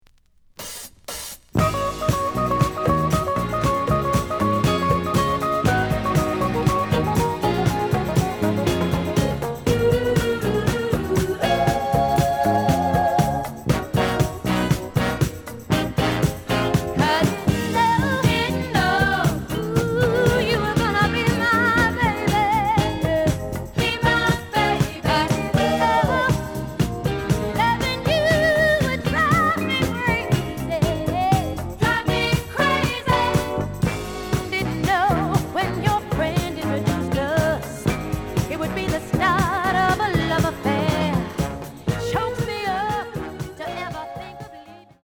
The listen sample is recorded from the actual item.
●Genre: Soul, 70's Soul